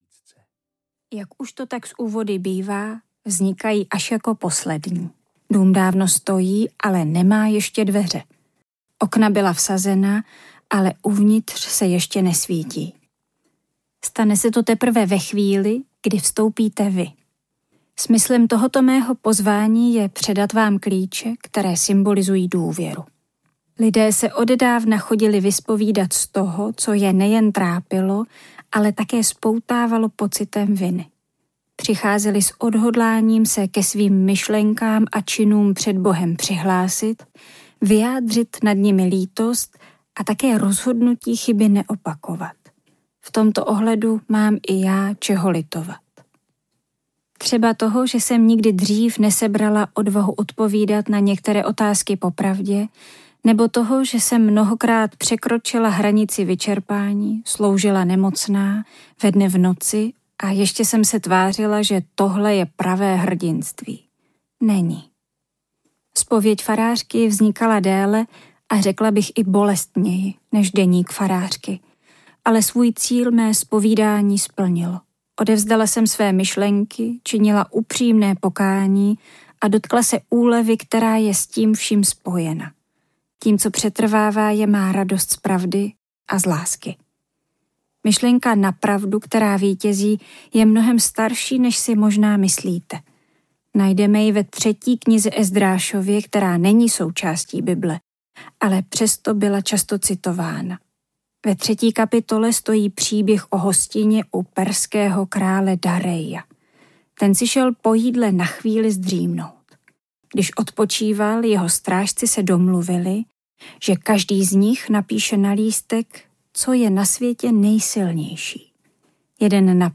Zpověď farářky audiokniha
Ukázka z knihy